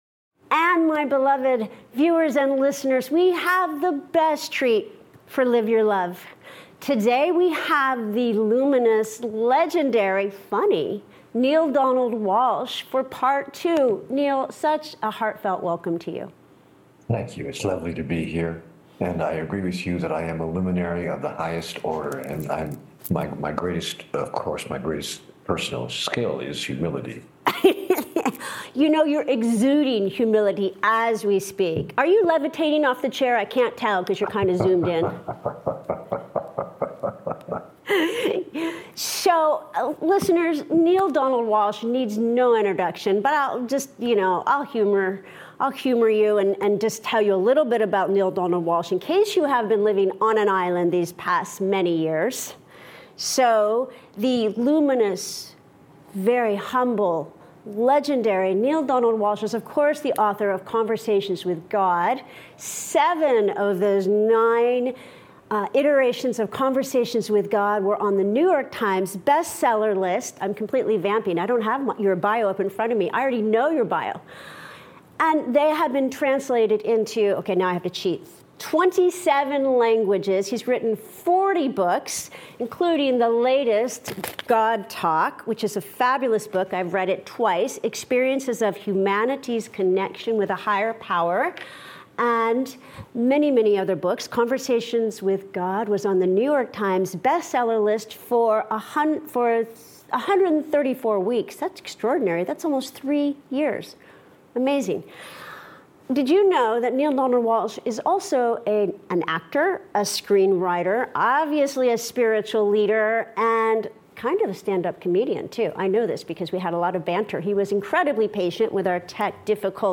I had the honor of sitting down for a second time with Neale Donald Walsch —a legend of an author whose words have shifted the way millions of us understand the Divine.